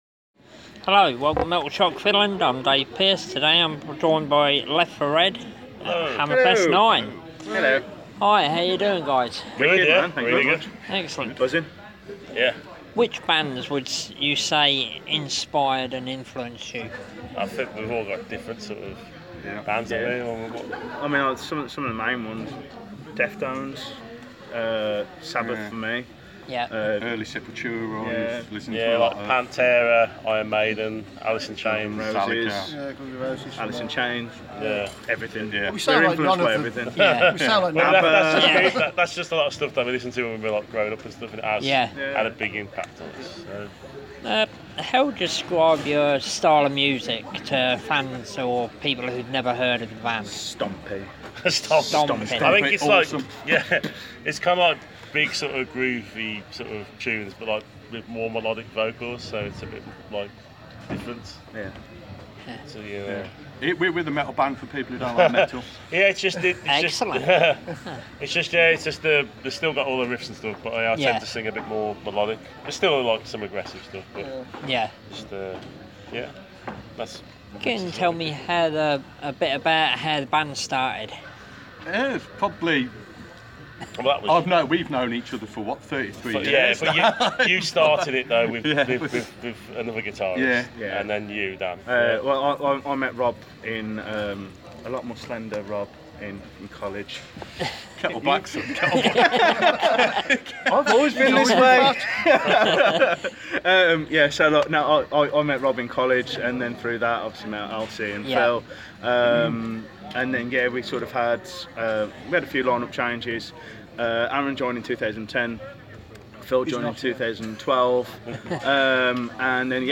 Interview With LEFT FOR RED At Hammerfest IX
left-for-red-interview-2017.mp3